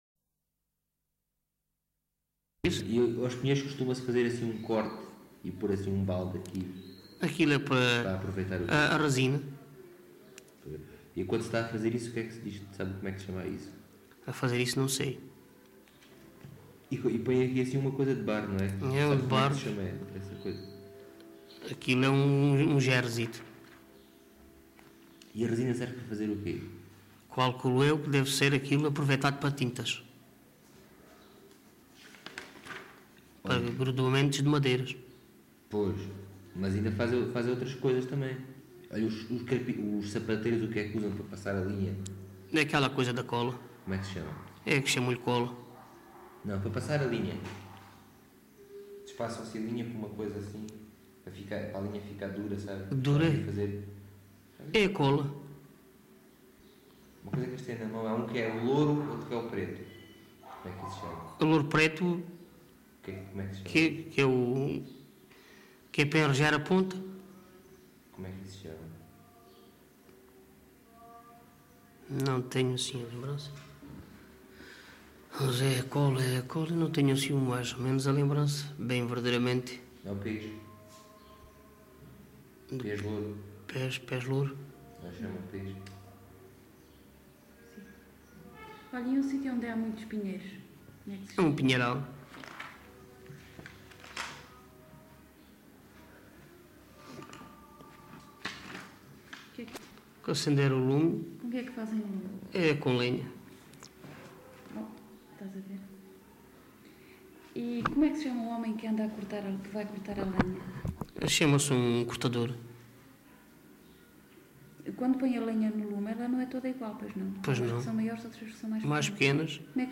LocalidadeSerpa (Serpa, Beja)